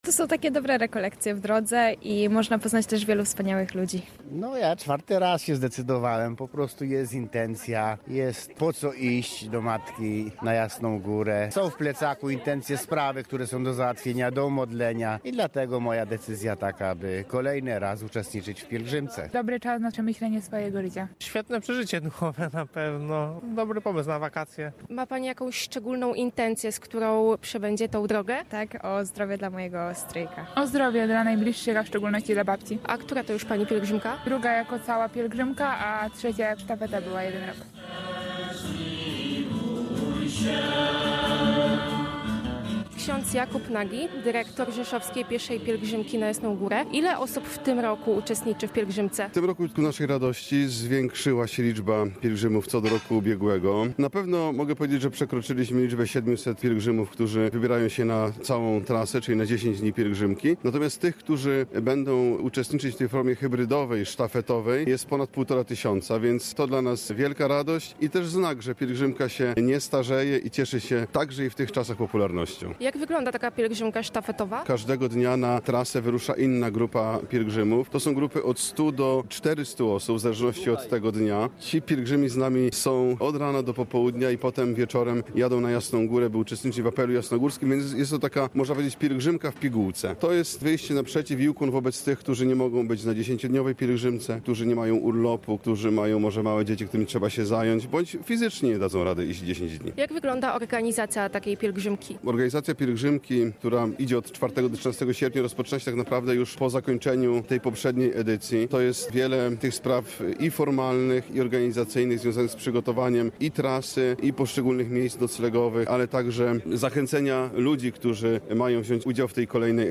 – powiedział podczas homilii biskup rzeszowski Jan Wątroba.